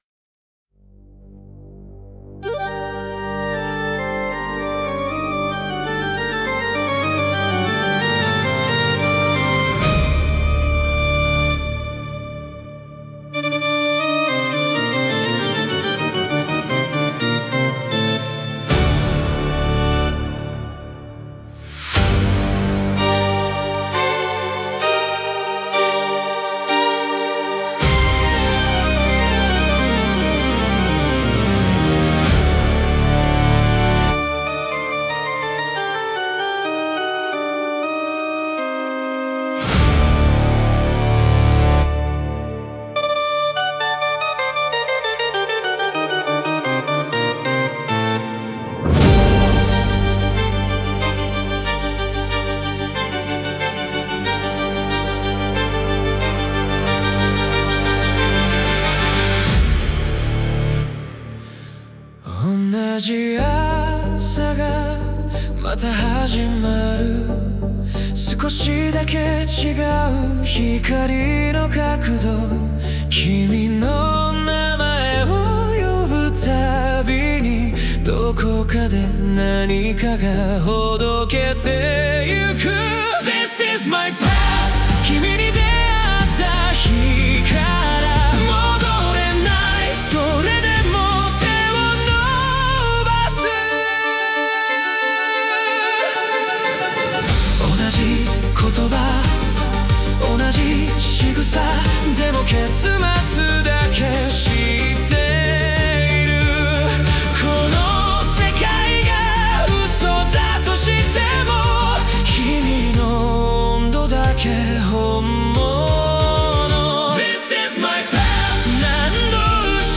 歌ものフリー素材 bgm音楽 無料ダウンロード 商用・非商用ともに登録不要で安心してご利用いただけます。
男性ボーカル